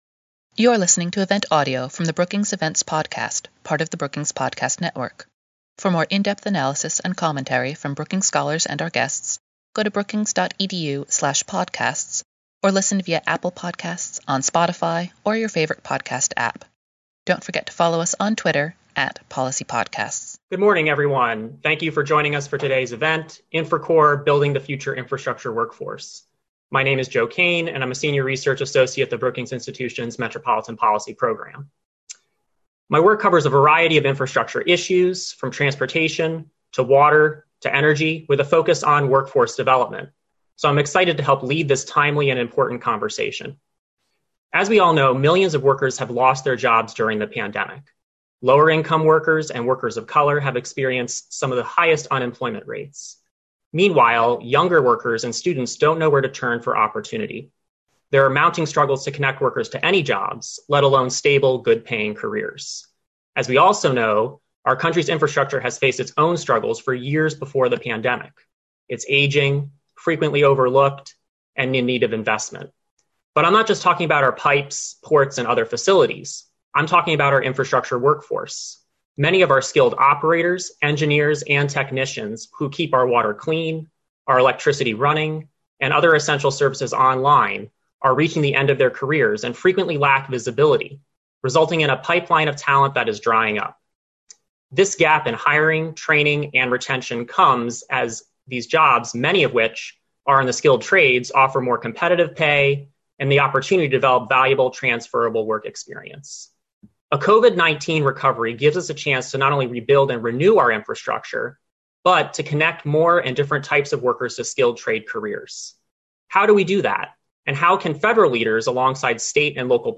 Register to join us on October 12, 2020, 10:00 – 11:00am ET for this webinar.
This multiyear federal program will aim to develop a diverse workforce in the skilled trades as part of an Infrastructure Stimulus Plan for the COVID-19 Recession . A panel of experts discussed the need, opportunity, and impact of such a program that has the potential to provide flexible learning and career opportunities in the skilled trades, especially for underrepresented, disadvantaged, and disconnected workers.